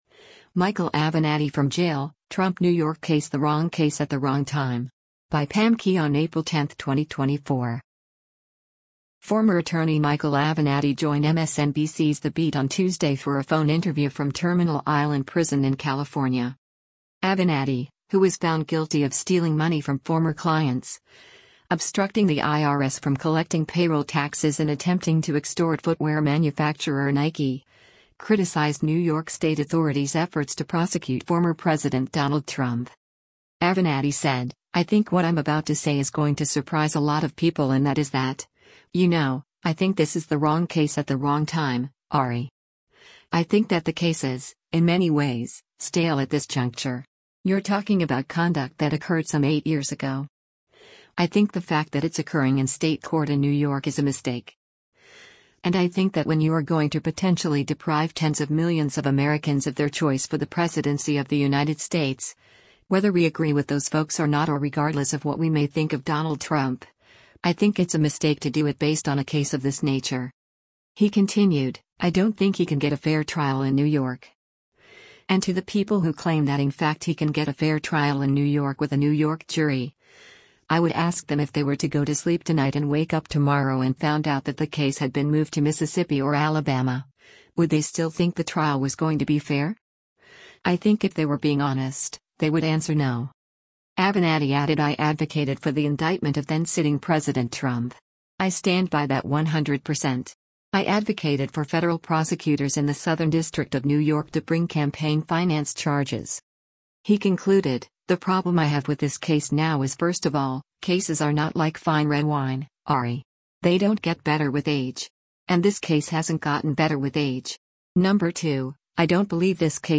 Former attorney Michael Avenatti joined MSNBC’s “The Beat” on Tuesday for a phone interview from Terminal Island Prison in California.